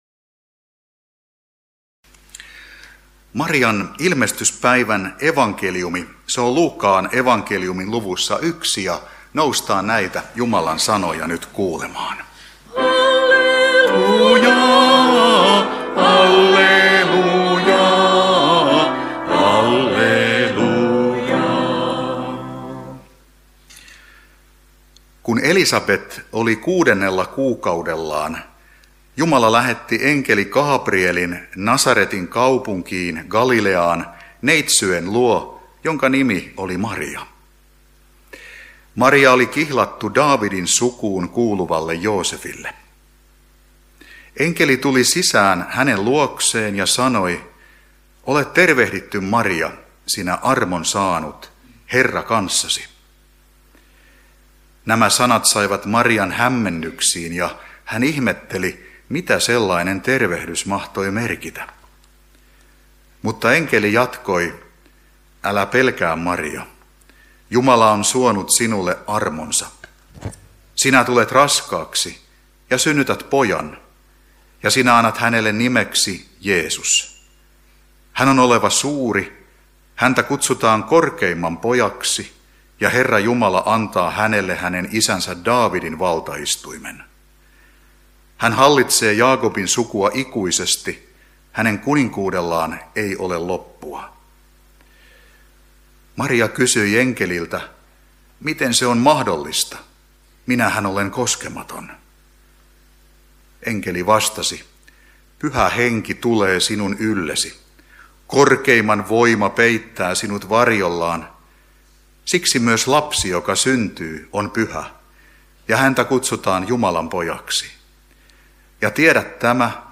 saarna Niinisalossa marianpäivänä Tekstinä Luuk. 1:26–38